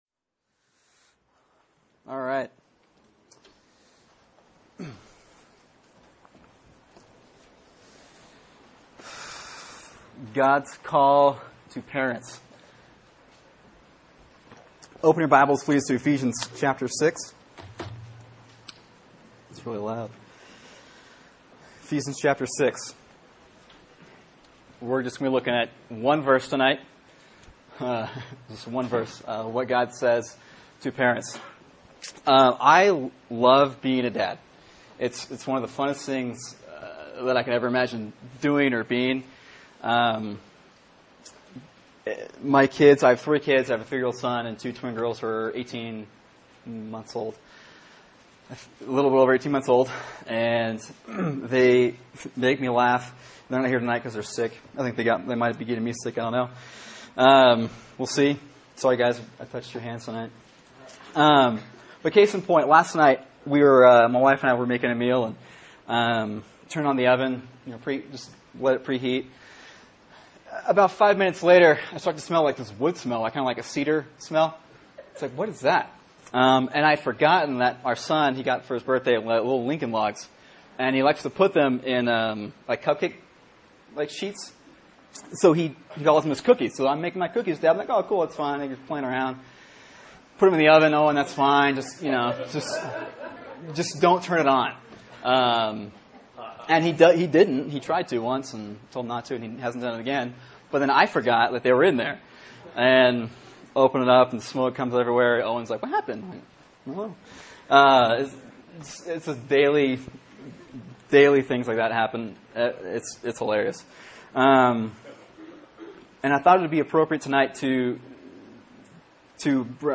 Sermon: Ephesians 6:4 “God’s Call to Parents” | Cornerstone Church - Jackson Hole